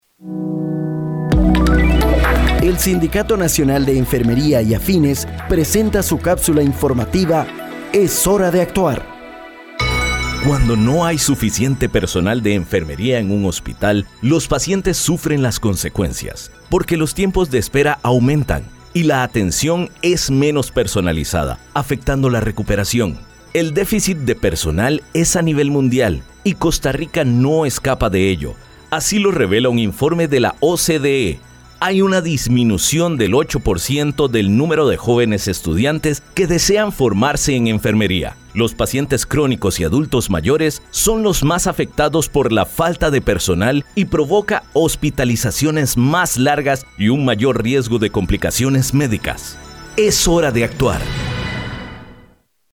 El Sindicato Nacional de Enfermería y Afines presenta su cápsula informativa “Es hora de actuar”.